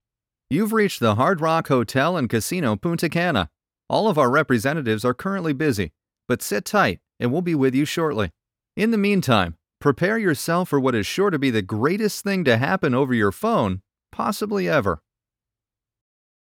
Male
My voice has been described as honest, warm, soothing, articulate, relatable, sincere, natural, conversational, friendly, powerful, intelligent and "the guy next door."
Phone Greetings / On Hold